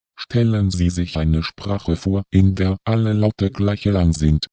Stellen Sie sich eine Sprache vor, in der alle Laute gleich lang sind (
Bsp05), eine unnatürliche Realisierung.